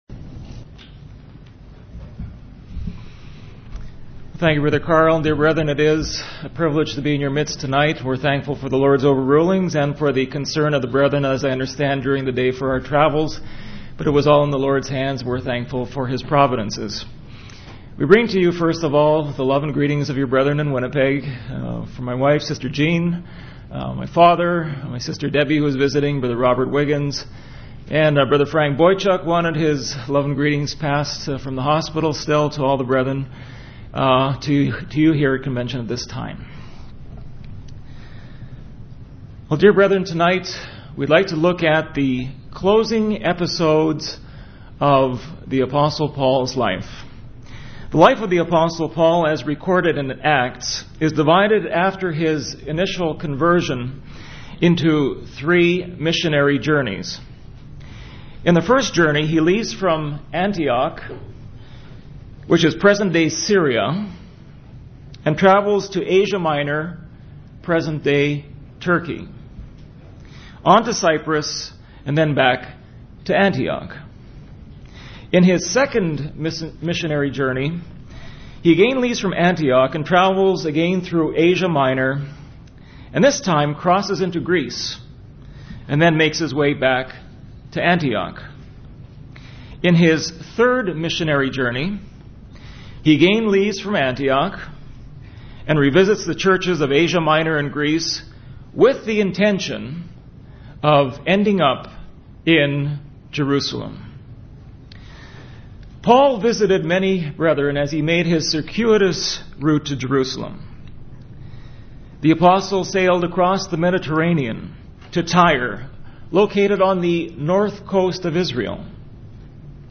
Series: Past Chicago Conventions
Service Type: Discourse